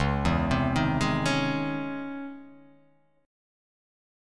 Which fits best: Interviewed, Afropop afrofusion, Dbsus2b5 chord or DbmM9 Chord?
DbmM9 Chord